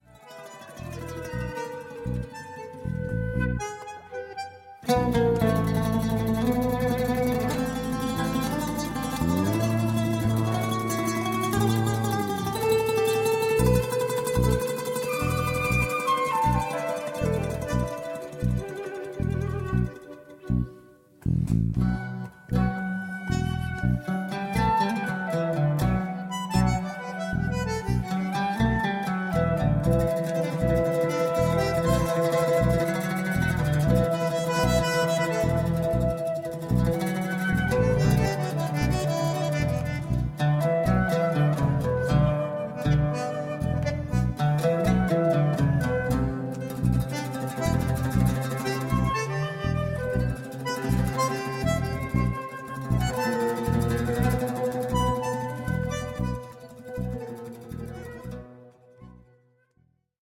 the traditional Arabic lute
a bare, sometimes minimalist, meditative album
a real must-have for all Ethno Jazz enthusiasts.
WORLD